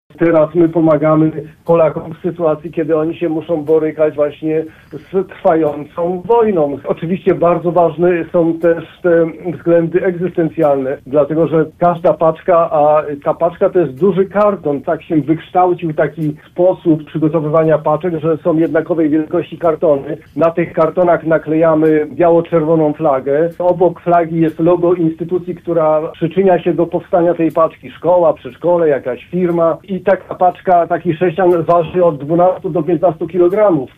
– Zbiórkę darów organizujemy już po raz 24., do tej pory jednorazowo wysyłaliśmy na Ukrainę nawet po 2-4 tys. paczek. – mówi inicjator przedsięwzięcia, senator Stanisław Gogacz.